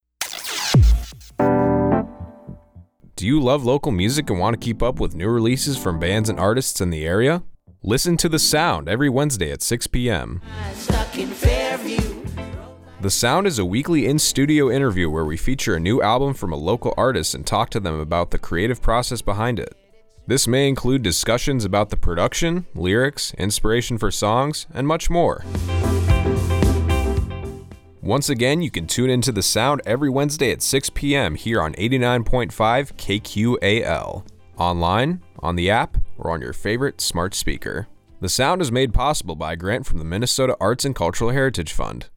Radio Promo